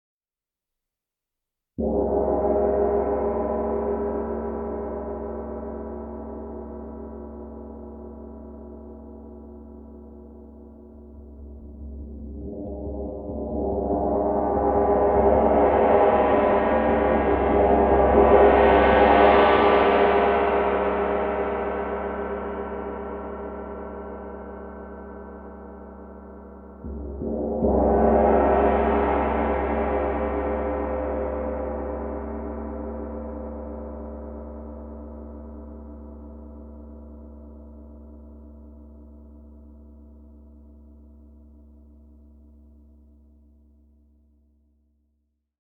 Meinl Sonic Energy 24" Om Gong, 136,10 Hz / C#2, Herzchakra (G24OM)
Produktinformationen "Meinl Sonic Energy 24" Om Gong, 136,10 Hz / C#2, Herzchakra (G24OM)" Die Meinl Sonic Energy Om Gongs werden in Deutschland von Hand gefertigt. Sie sind auf den Ton "Om" gestimmt, der eine der heiligsten Silben des Hinduismus ist.
Die immense Größe und Kraft dieses Instruments wird jeden Zuhörer in Ehrfurcht und Staunen versetzen.